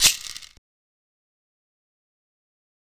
Shaker Perc.wav